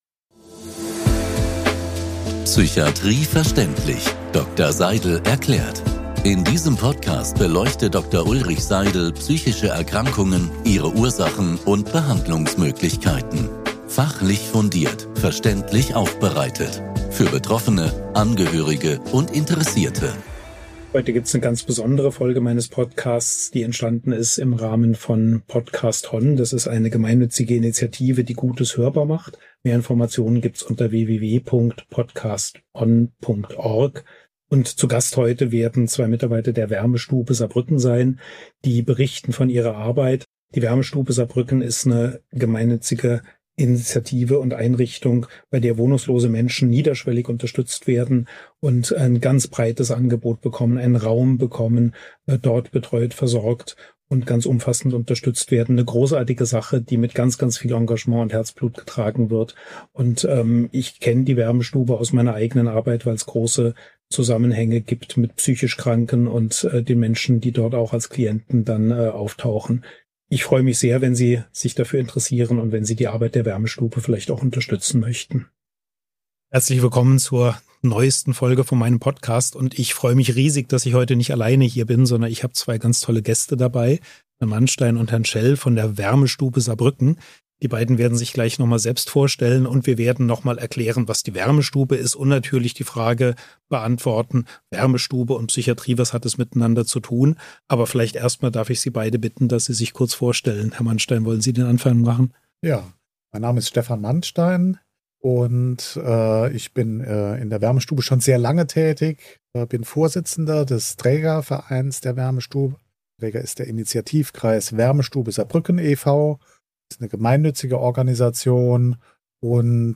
Wir sprechen darüber, was die Wärmestube für wohnungslose und armutsbetroffene Menschen leistet, warum niederschwellige Hilfe so wichtig ist und welche Rolle psychische Erkrankungen, Suchterkrankungen und soziale Ausgrenzung im Alltag vieler Betroffener spielen. Meine Gäste geben sehr offene und eindrückliche Einblicke in ihre Arbeit: in die Herausforderungen, aber auch in die menschlichen Begegnungen, die zeigen, wie wichtig Würde, Aufmerksamkeit und ein geschützter Raum für Menschen in schwierigen Lebenslagen sind. Eine Folge über Armut, Wohnungslosigkeit, psychische Erkrankungen, Suchtthemen und darüber, warum Hilfe oft dort beginnt, wo Menschen überhaupt erst einmal angenommen werden.